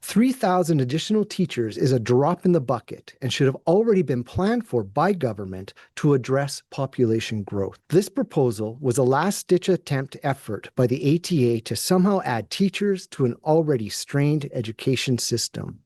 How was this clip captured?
Later in the afternoon of Friday, August 29, the ATA held a press conference in Edmonton in response to the Alberta Government’s announcement.